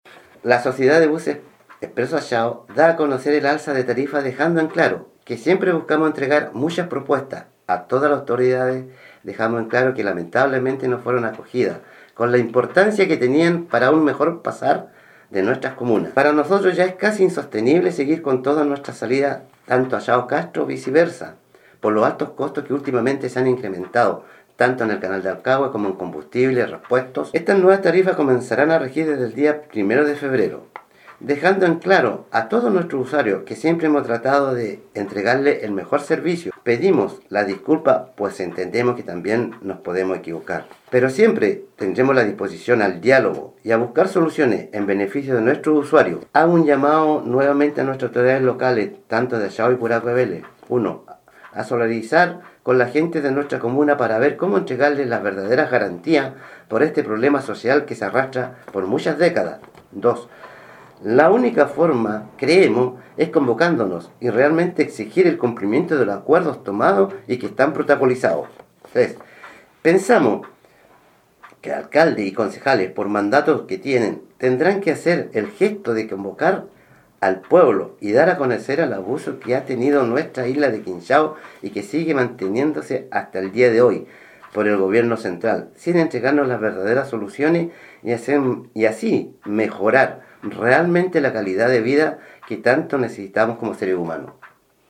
26-DECLARACION-MICROBUSEROS-ACHAO-TARIFA.mp3